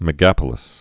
(mĭ-găpə-lĭs, mĕ-)